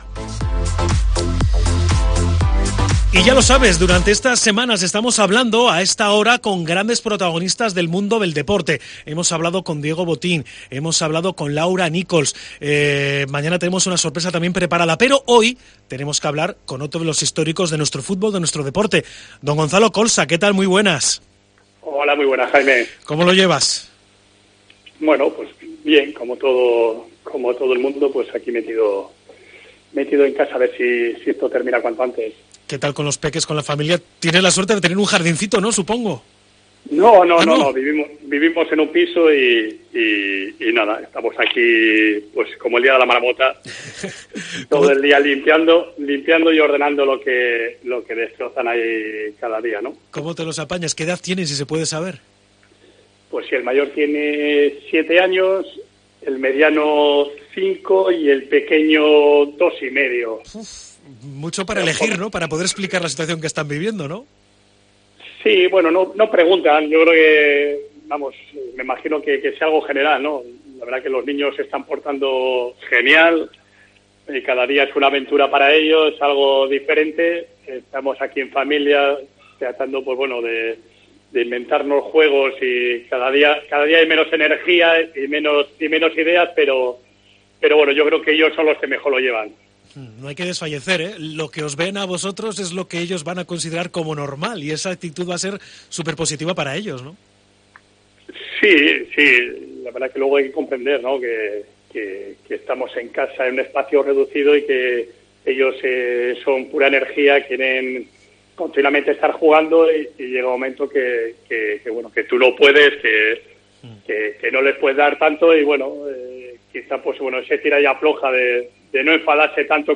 Gonzalo Colsa, en su salón